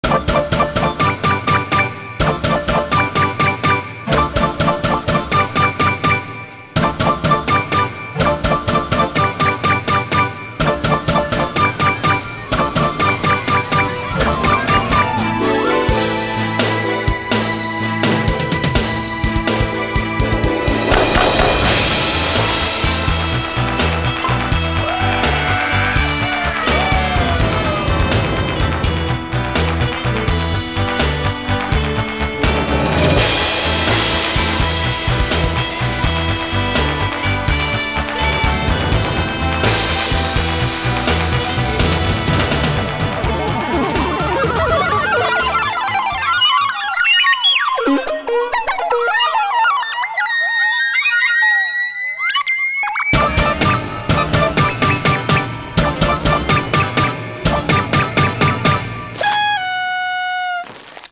Genre: Fusion.